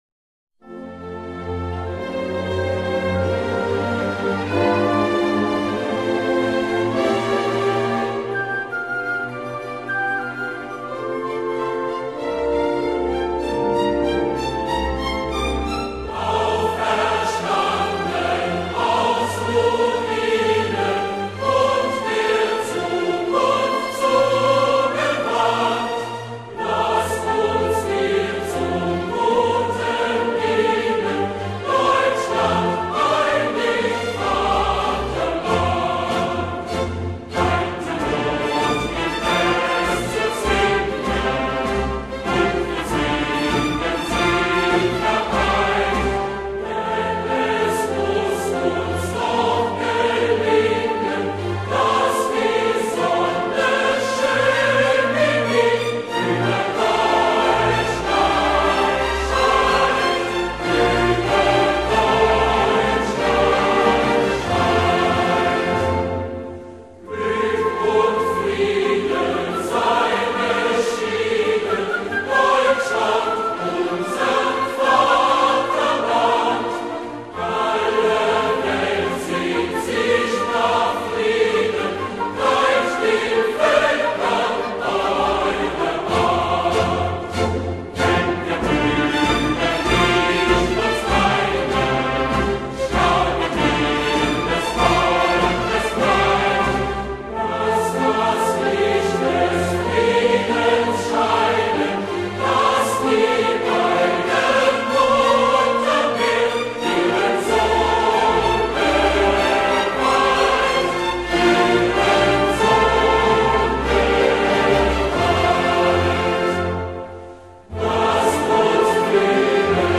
Auferstanden_aus_Ruinen_(Vocal).ogg